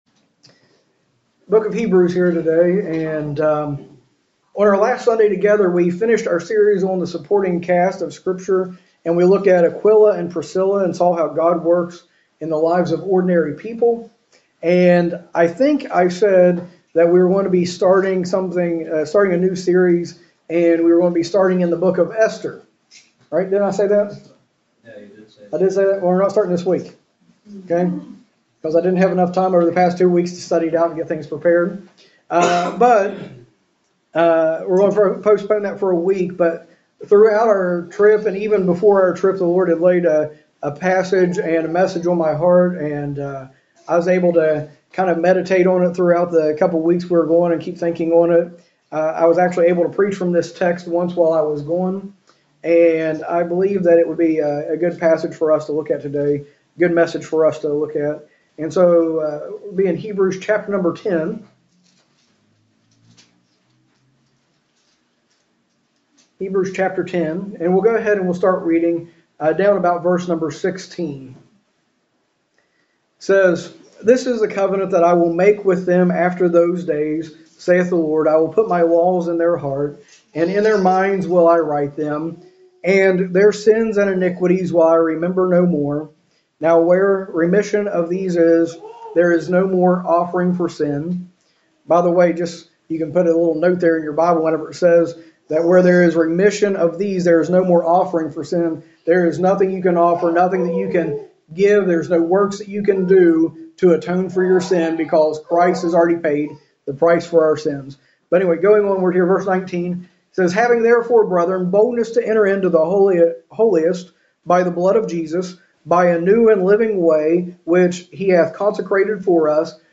A message from the series "Series Breaks." Though Hebrews 10:25 is often given like a command, we find that, when taken in context, it shows we need the church and the church needs us. The church is a gift from God to his saints for their good and His glory.